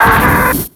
Cri de Boskara dans Pokémon X et Y.